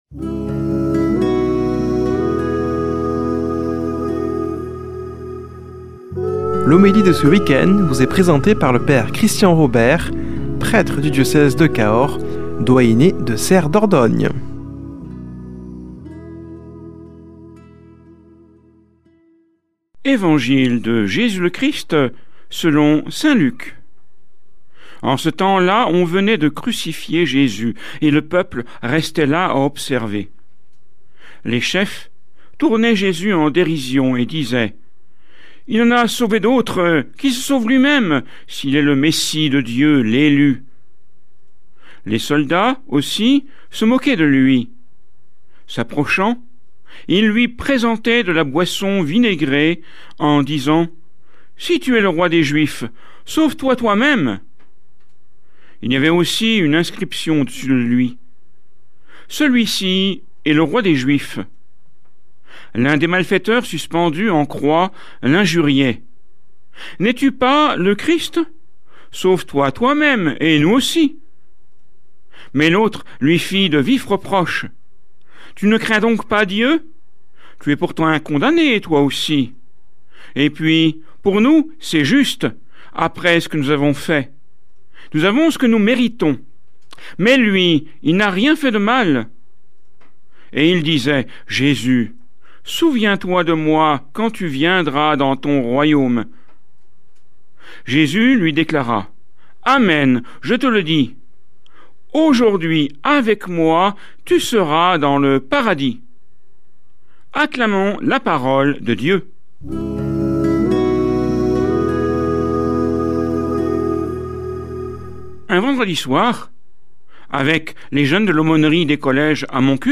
Homélie du 22 nov.